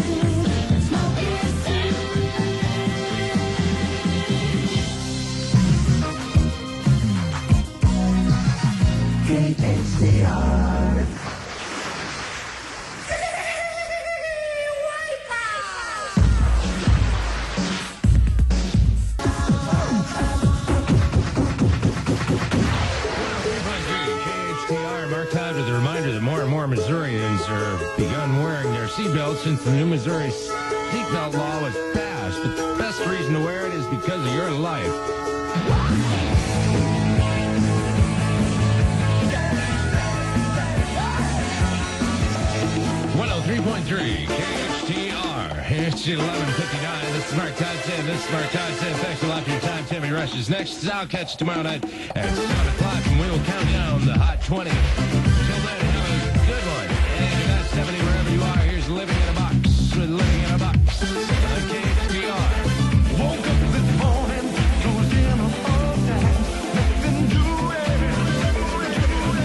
aircheck